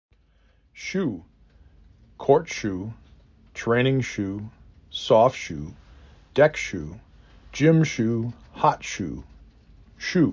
4 Letters, 1 Syllable
2 Phonemes
S U